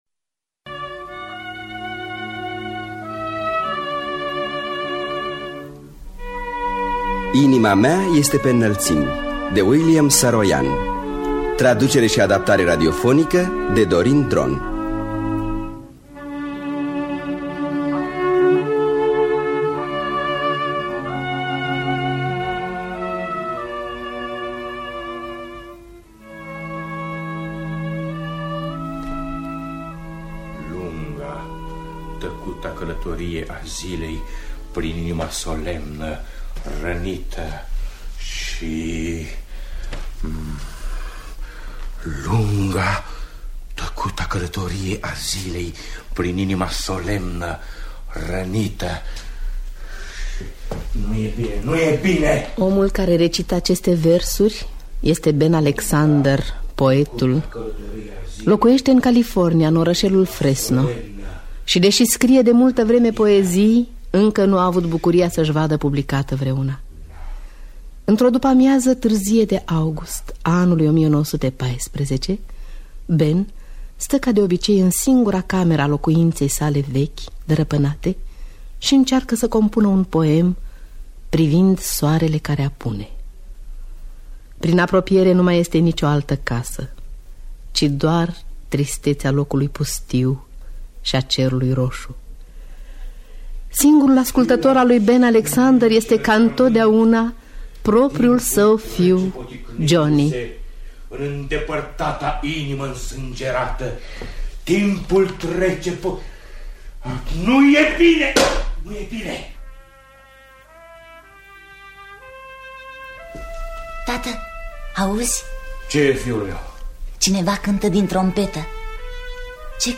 Regia artistică: Lucian Pintilie.